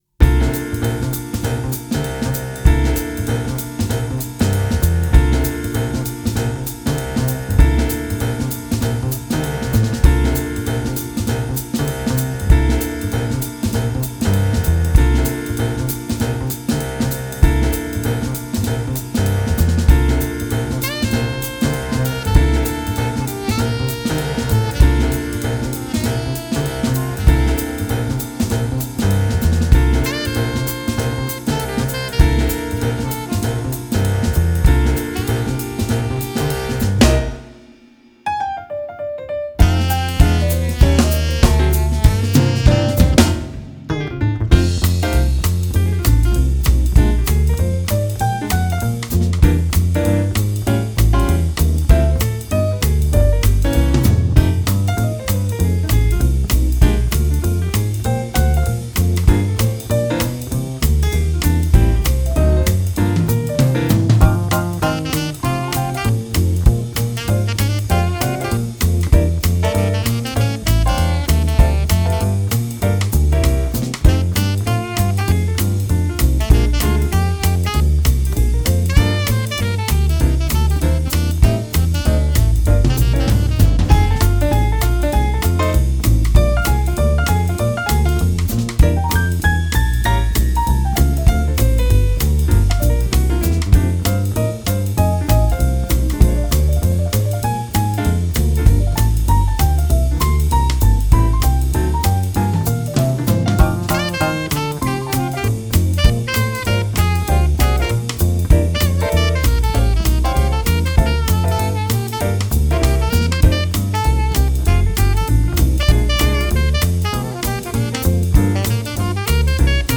1. Challenge 2026 - Jazz (Ergebnisse)
4/4 auf 195 bpm. 3 Takte Stille -> 2 Takte Einzähler.
Meine Mastering-Kette halte ich dabei subtil mit etwas Kompression, sachtem EQing und einer Tonband-Simulation.